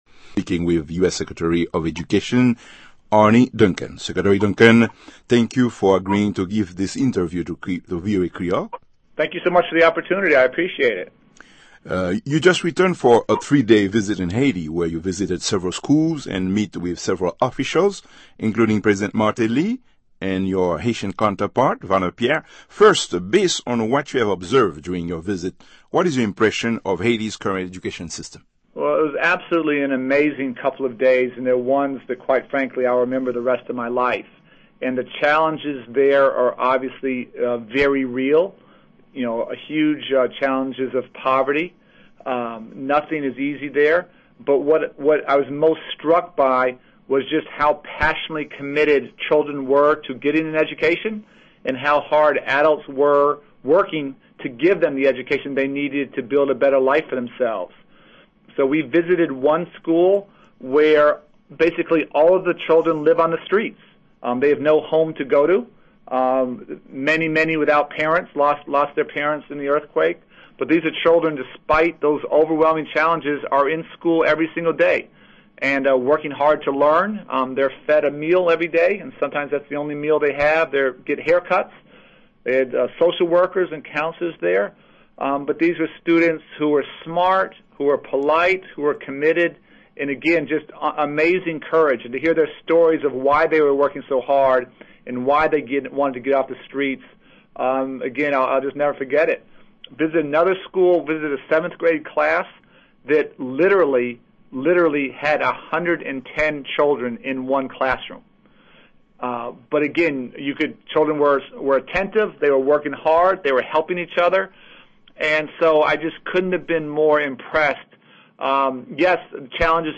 interview with US Secretary of Education Arne Duncan